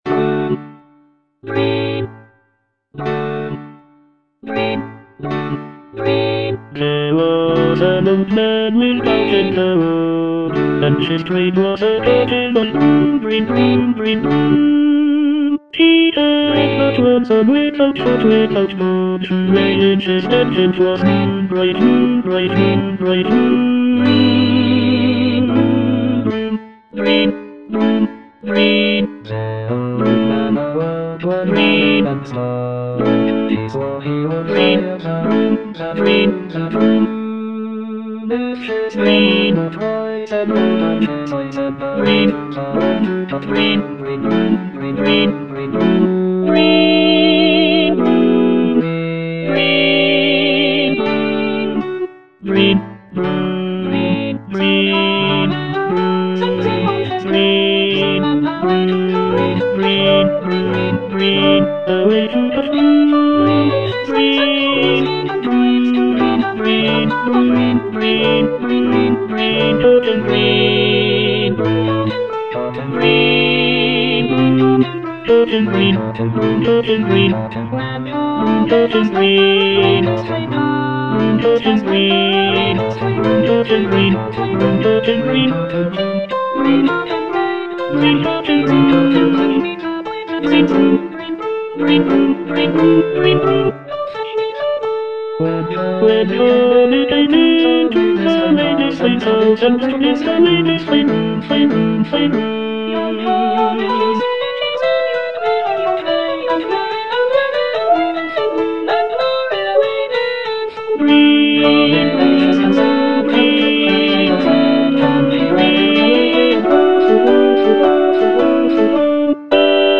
Tenor I (Emphasised voice and other voices)